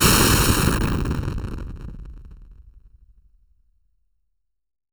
THUNDER   -S.WAV